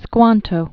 (skwŏntō) Originally Ti·squan·tum (tĭ-skŏntəm) Died 1622.